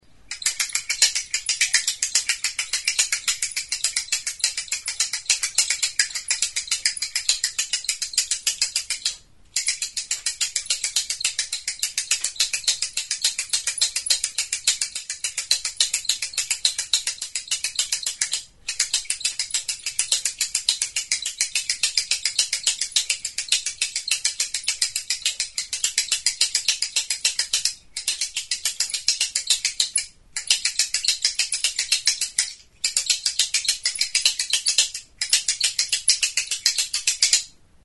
Recorded with this music instrument.
Idiophones -> Struck -> Indirectly
Kirtena esku tartean bi zentzuetara jiratuz, zeharka sartutako makilak alboetako hortzen kontra jotzerakoan hotsa ematen du.
WOOD